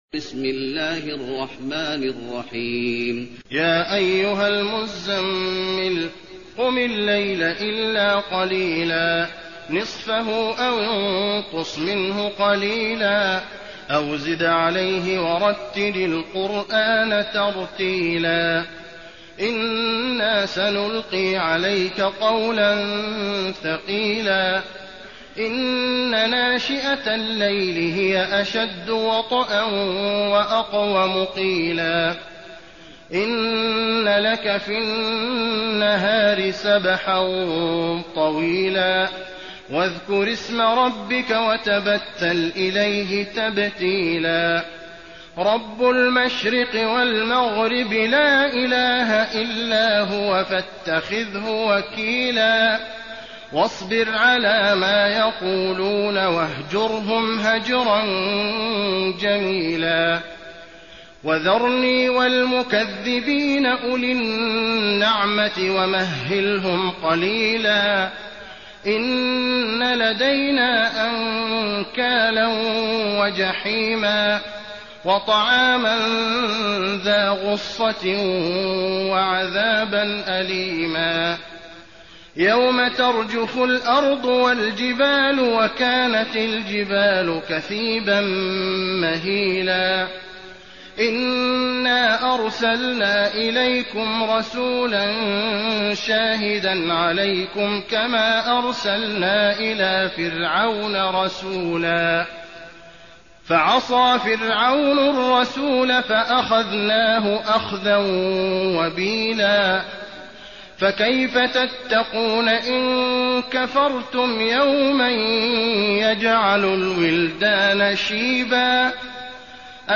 المكان: المسجد النبوي المزمل